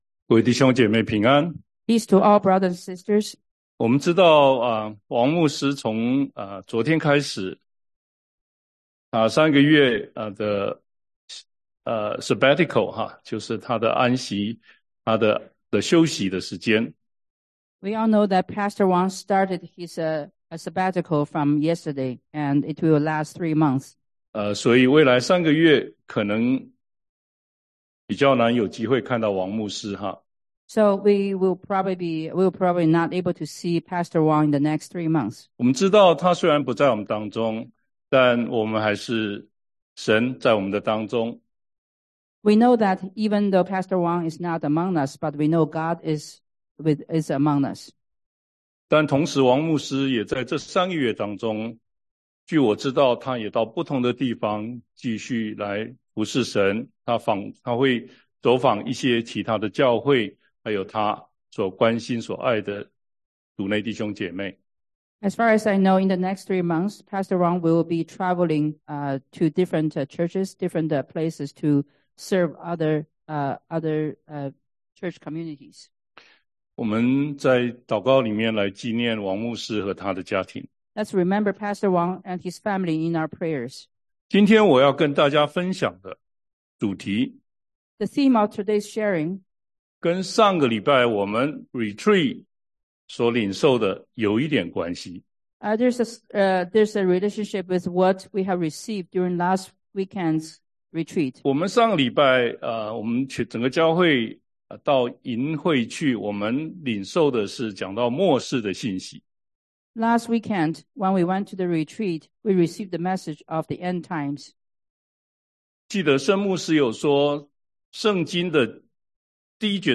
中文講道